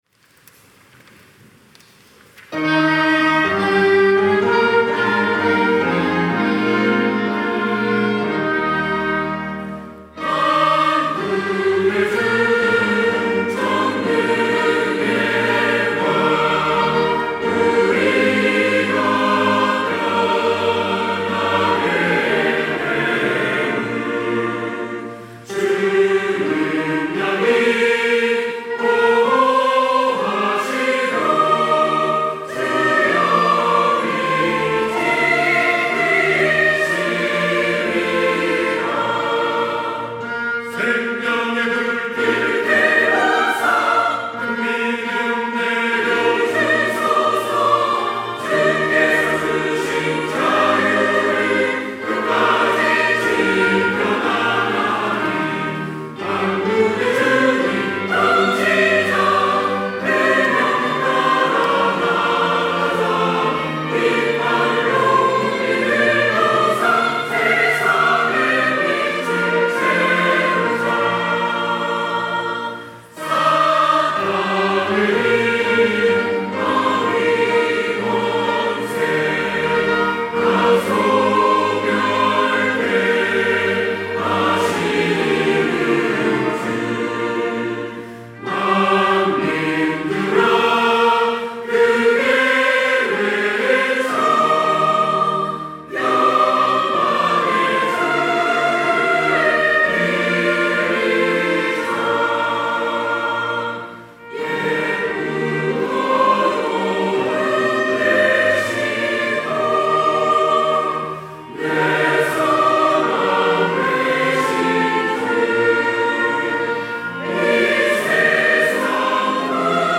호산나(주일3부) - 만군의 주 전능의 왕
찬양대